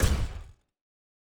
Footstep Robot Large 2_07.wav